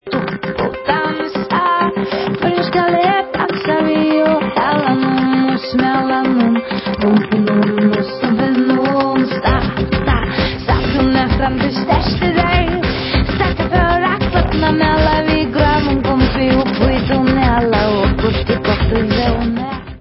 sledovat novinky v oddělení Folk